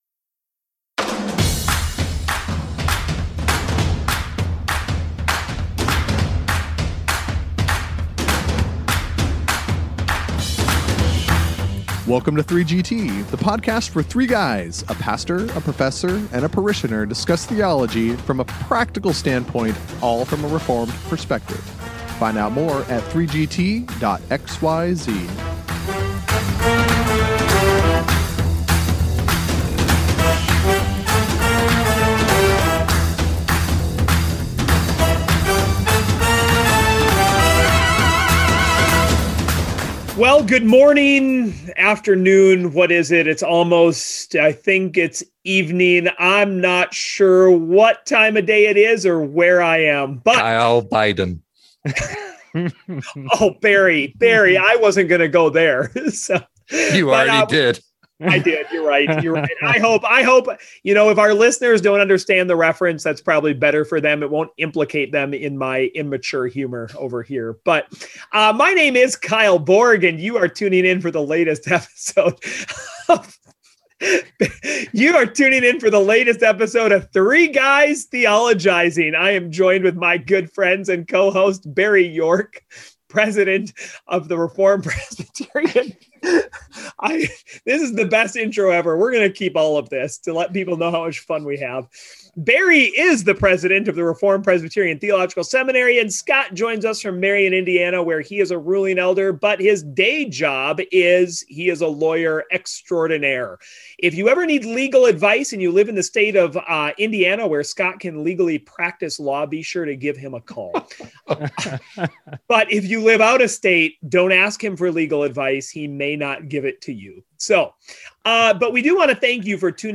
The 3GTers interact on what they would do when asked the following questions.